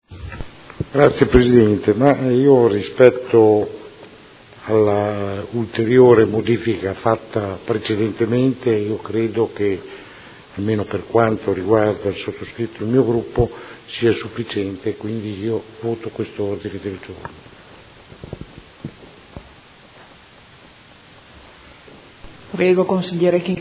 Seduta del 17/11/2016. Dibattito su Mozione presentata dal Gruppo Movimento Cinque Stelle avente per oggetto: Spese quotidiani presso edicole